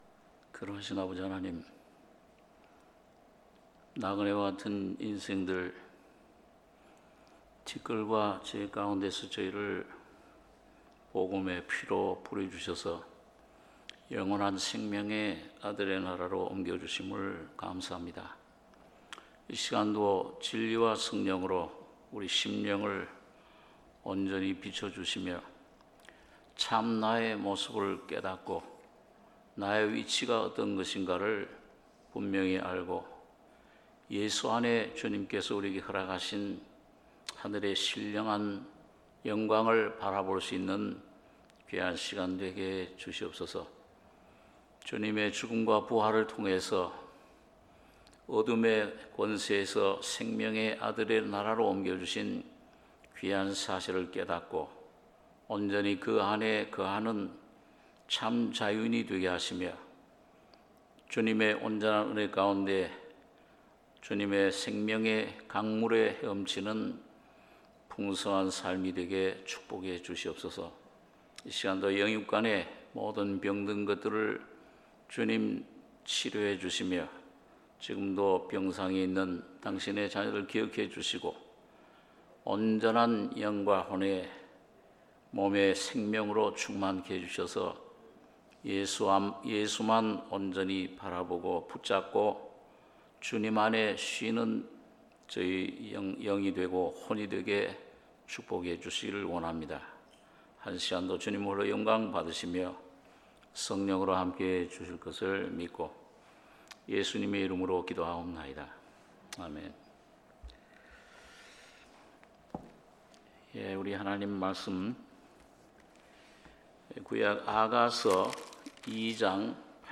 수요예배 아가 2장 8~17절